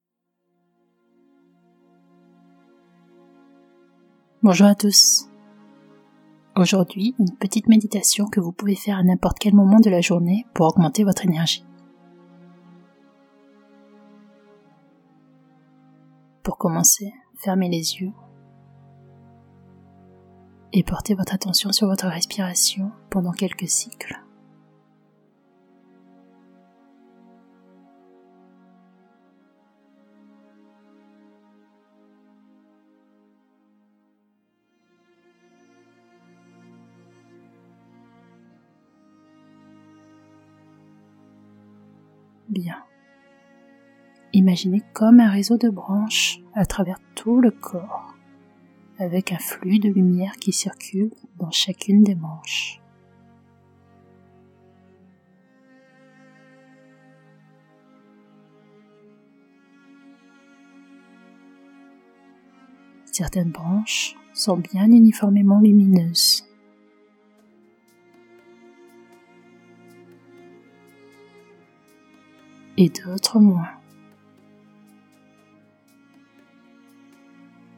Méditation guidée, Boostez votre énergie en 5 min - Bulles de Légèreté
extrait-Meditation-guidee-Boostez-votre-energie-en-5-minutes.mp3